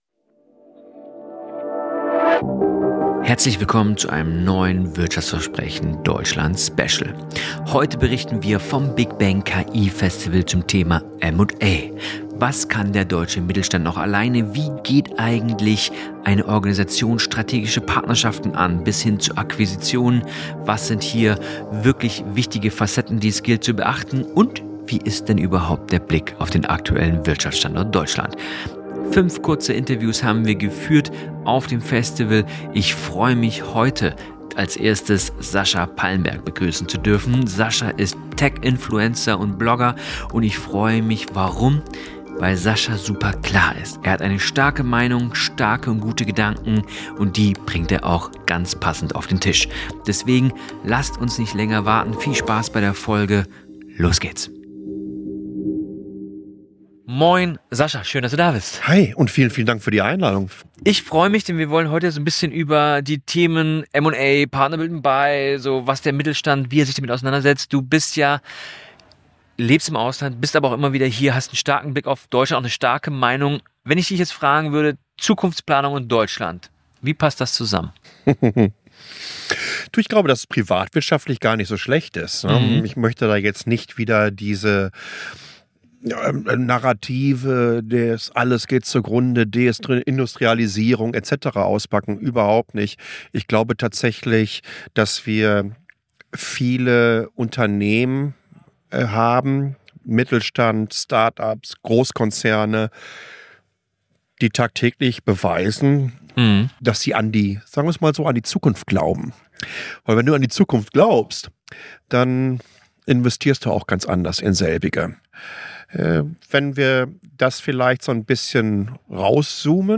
Auf dem Big Bang KI Festival haben wir fünf exklusive und intensive Interviews geführt...